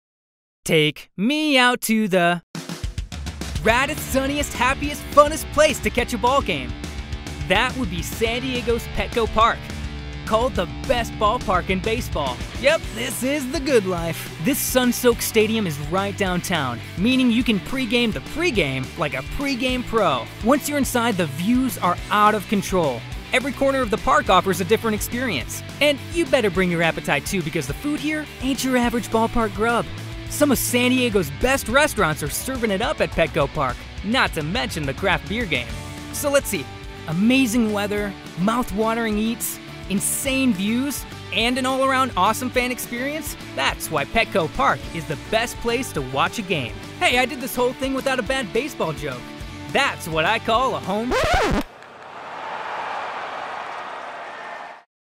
Animated, authentic, boyish, compassionate, endearing, conversational, "Guy Next Door", genuine, nurturing, real, reassuring, sincere, trustworthy and honest, warm and friendly, youthful
Energetic, Fun, Quirky
Commercial